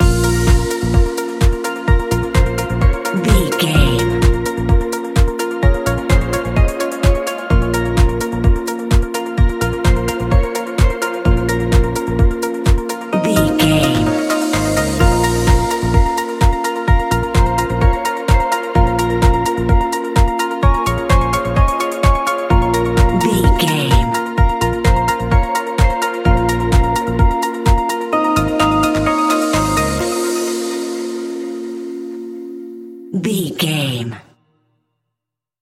Aeolian/Minor
B♭
groovy
uplifting
energetic
drums
drum machine
synthesiser
bass guitar
funky house
upbeat